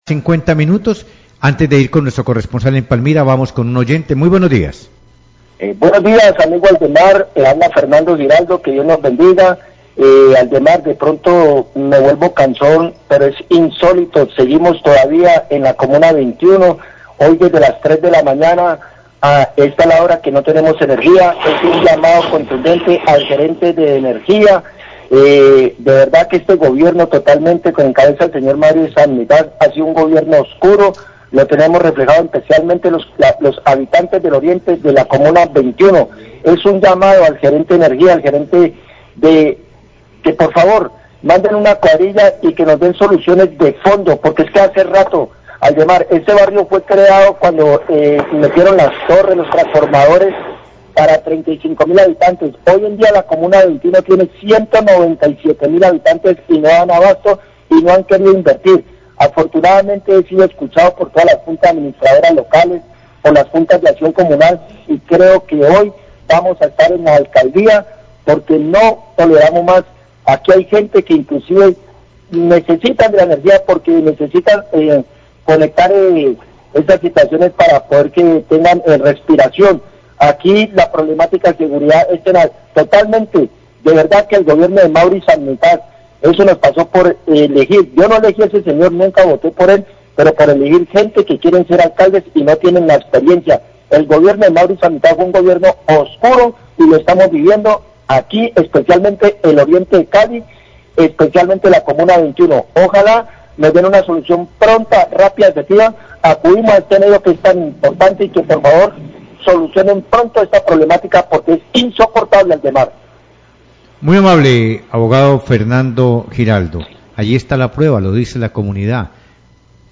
OYENTE RECLAMAN QUE ESTÁN SIN ENERGÍA EN LA COMUNA 21, VIVA LAS NOTICIAS, 649am
Radio